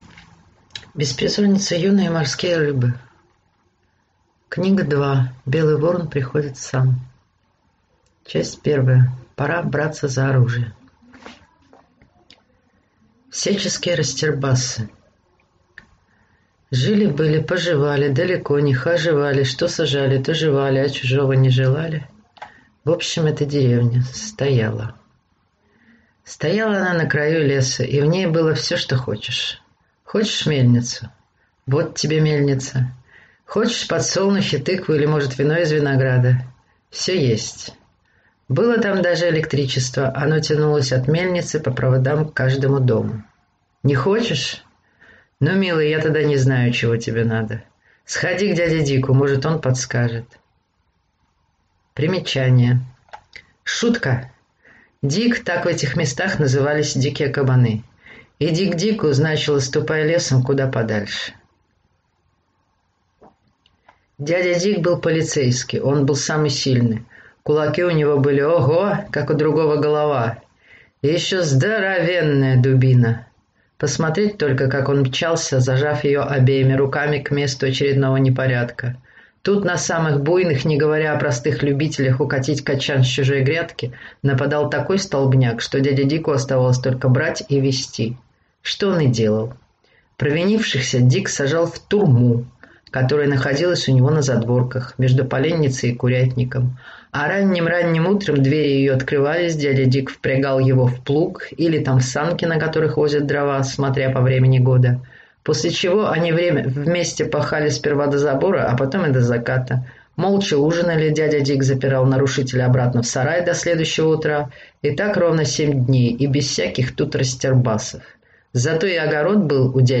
Аудиокнига Беспризорница Юна и морские рыбы. Книга 2. Белый Ворон приходит сам | Библиотека аудиокниг